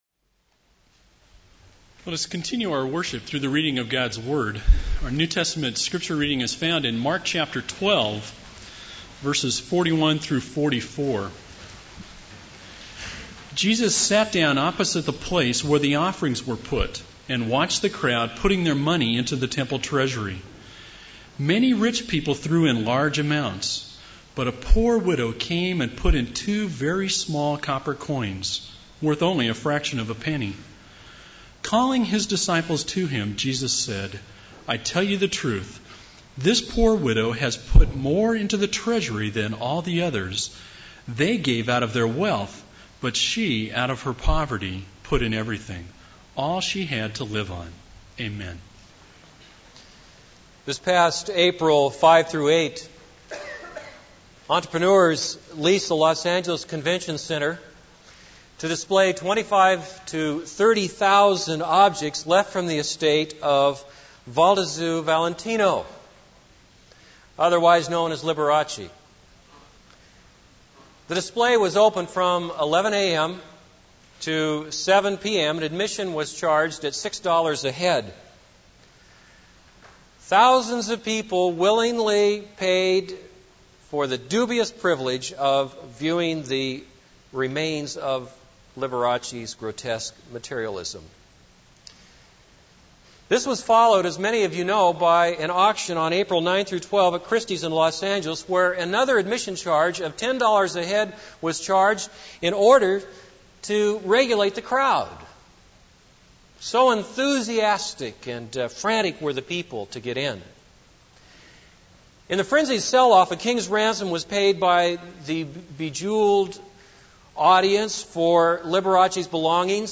This is a sermon on Mark 12:41-44.